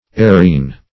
ouarine - definition of ouarine - synonyms, pronunciation, spelling from Free Dictionary Search Result for " ouarine" : The Collaborative International Dictionary of English v.0.48: Ouarine \Oua`rine"\, n. [F.]